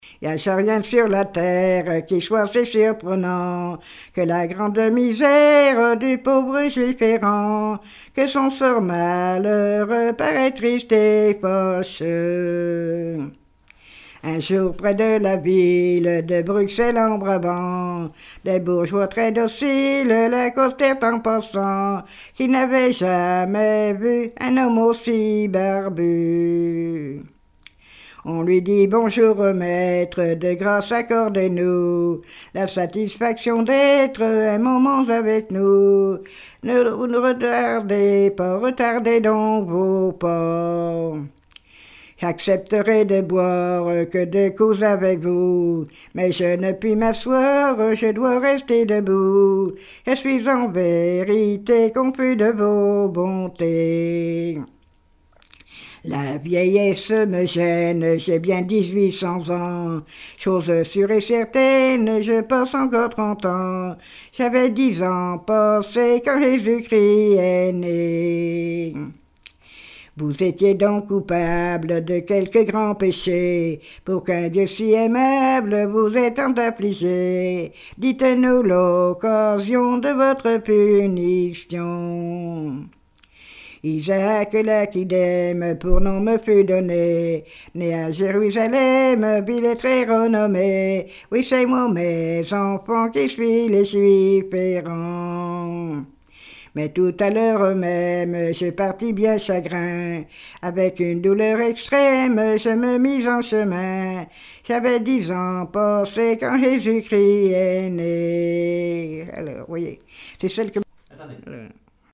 répertoire de chansons populaires
Pièce musicale inédite